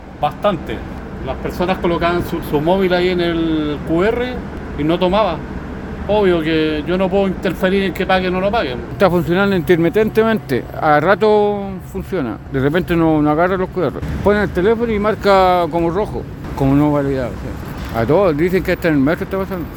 Así lo relataron algunos pasajeros y también inspectores que revisan que los usuarios efectivamente paguen su pasaje en los paraderos.